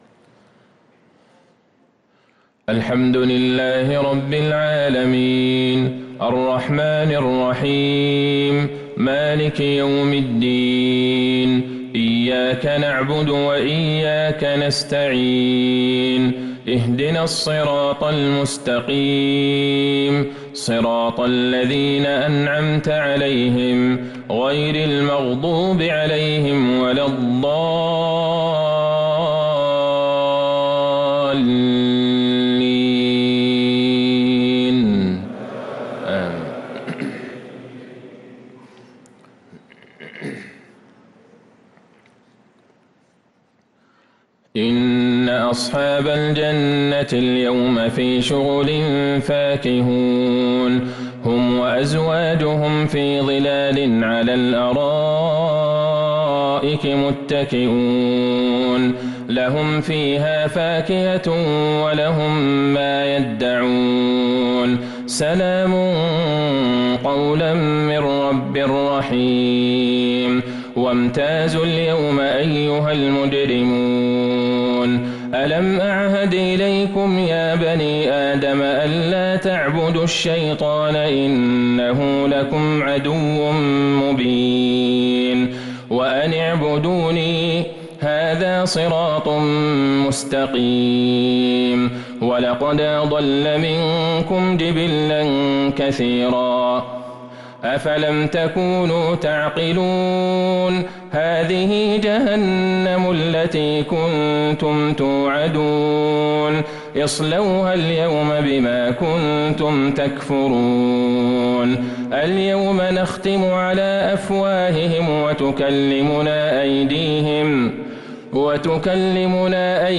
صلاة العشاء للقارئ عبدالله البعيجان 28 صفر 1445 هـ